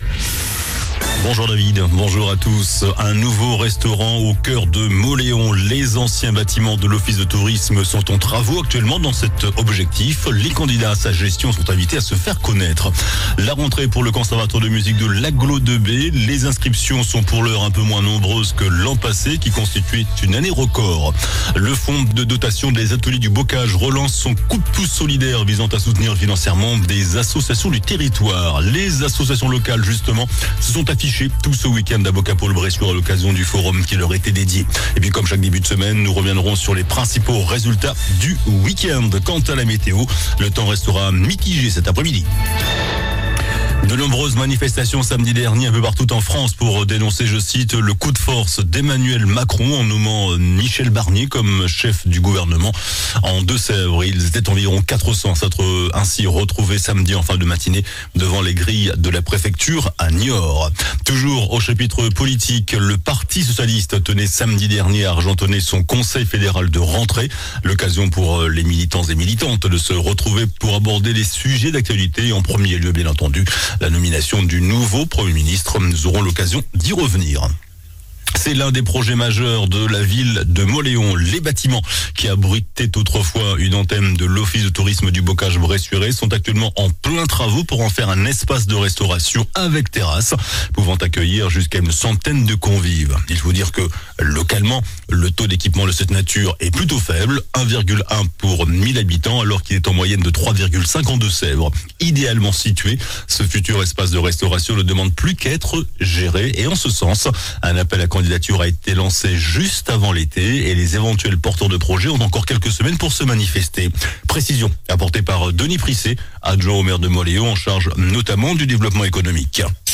JOURNAL DU LUNDI 09 SEPTEMBRE ( MIDI )